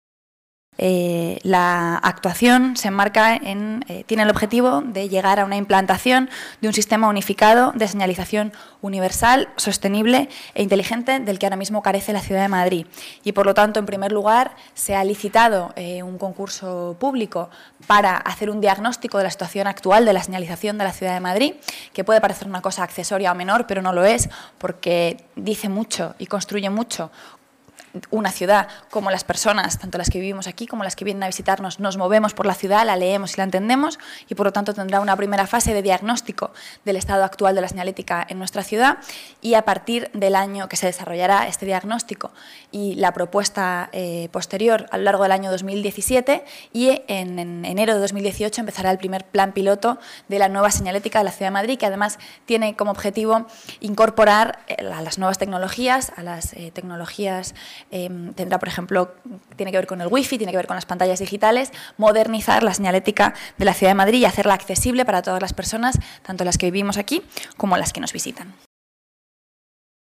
Nueva ventana:Rita Maestre, portavoz del Gobierno municipal